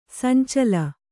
♪ sancala